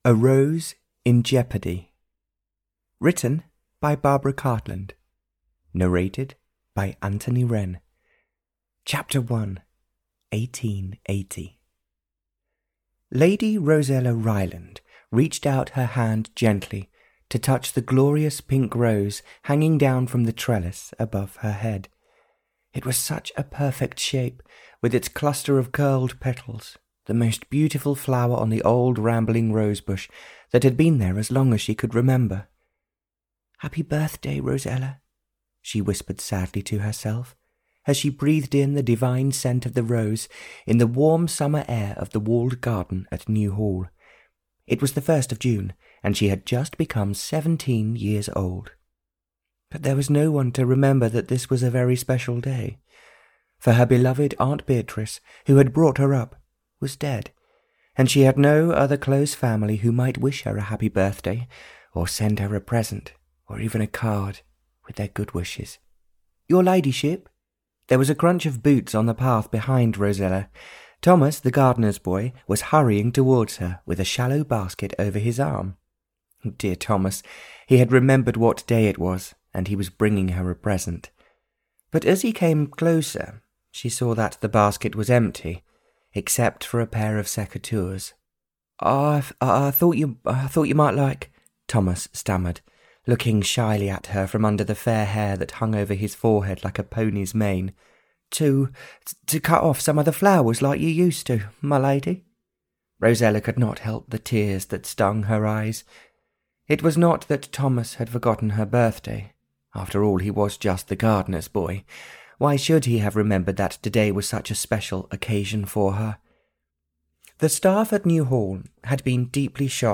Audio knihaA Rose in Jeopardy (Barbara Cartland’s Pink Collection 100) (EN)
Ukázka z knihy